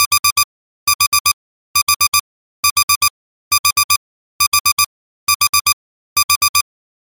alarm_clock_beep_tone
Tags: Sci Fi Play